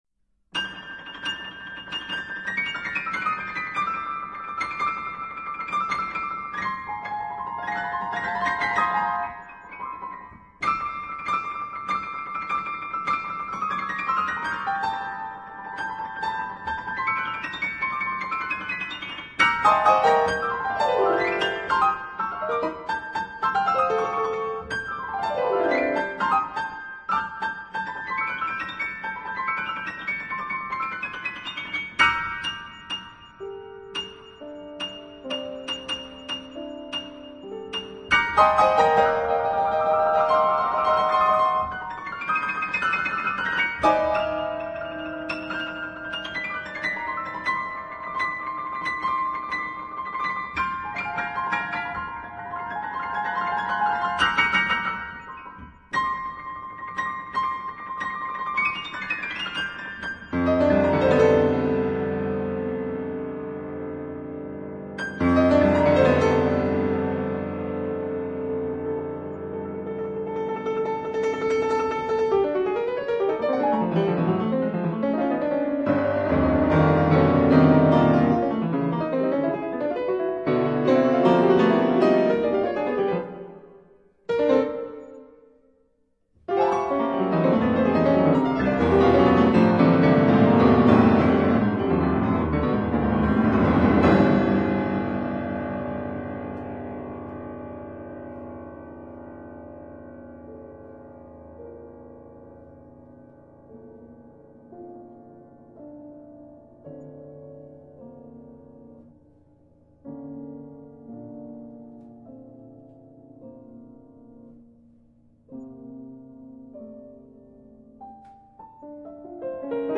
per pianoforte / for piano (2011)
Piano